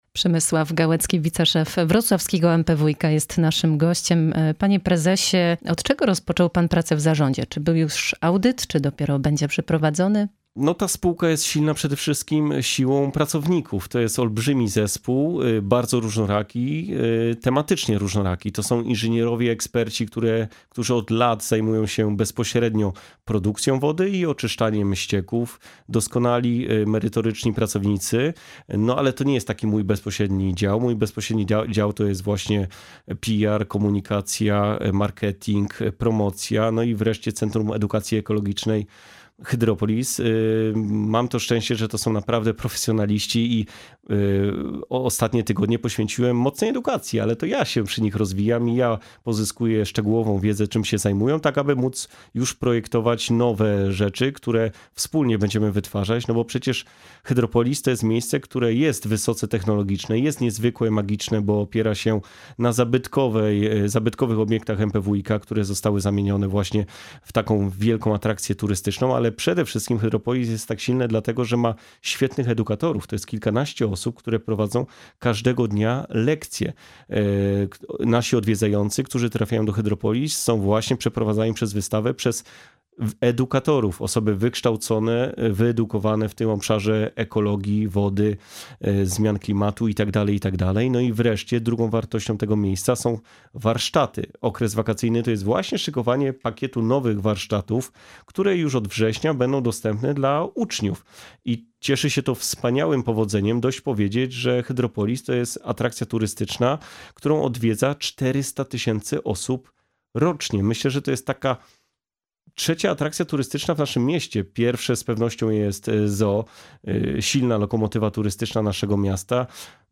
Pytamy w wywiadzie.